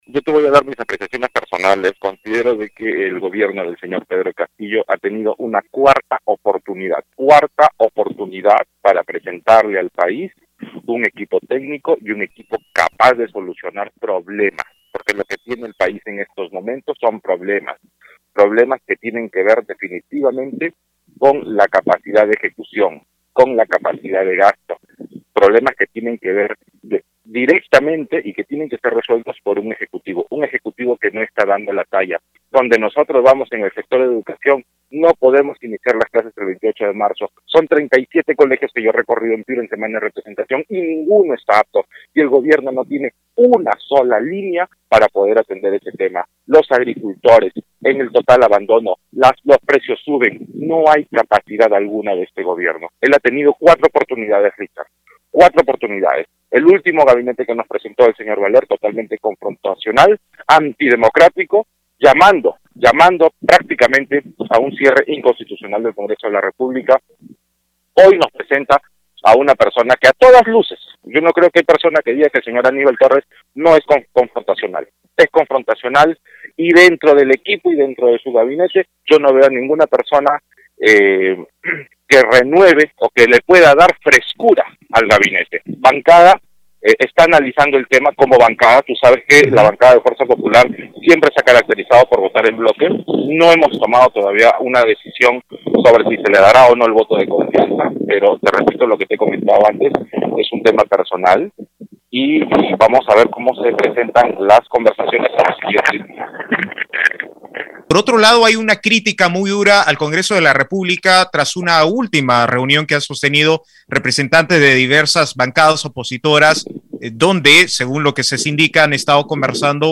En conversación con Radio Uno, el congresista de Fuerza Popular, Eduardo Castillo, se pronunció luego de que el semanario Hildebrandt en sus trece reveló que la jefa de la Mesa Directiva, María del Carmen Alva, y voceros de diferentes bancadas sostuvieron una reunión para coordinar cómo vacar al presidente de la República, Pedro Castillo.